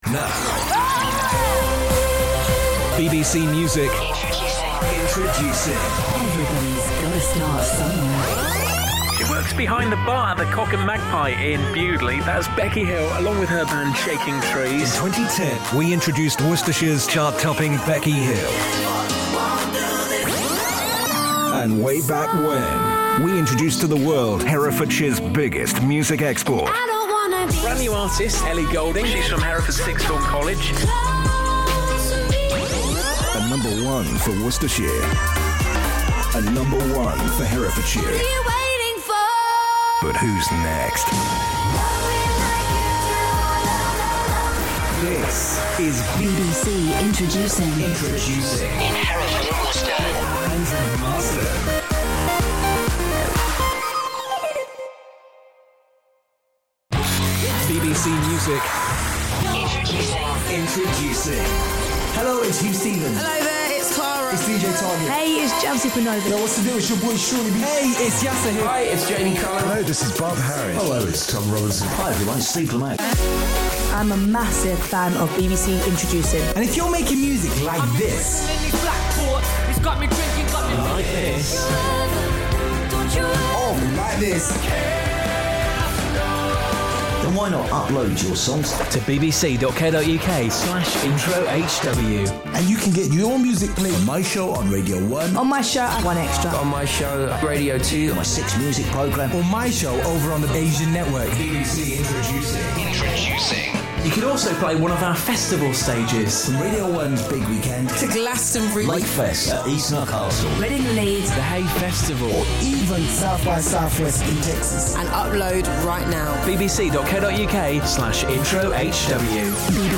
Radio programme imaging for BBC Hereford & Worcester.
The three openers rotate every week and reflect the unique sound of BBC Introducing - dynamic and fresh, plus a mix of local and national elements.